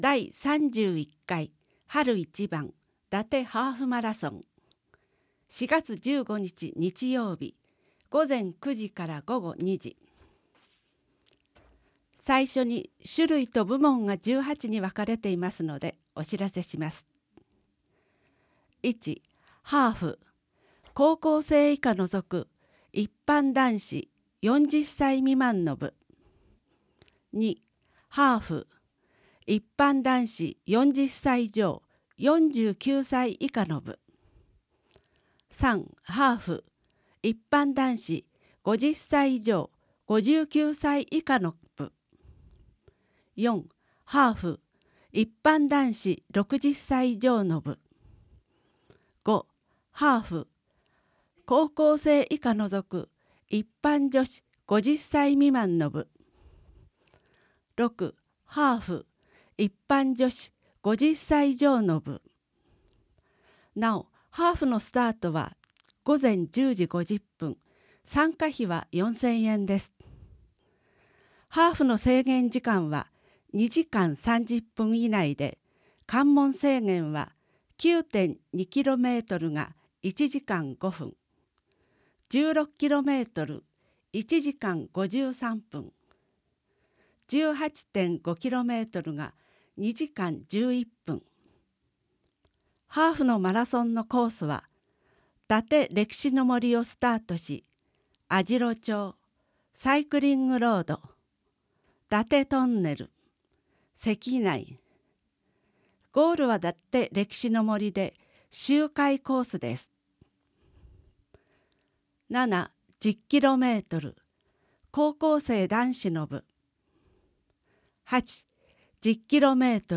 内容をカセットテープに録音し、配布している事業です。
■朗読ボランティア「やまびこ」が音訳しています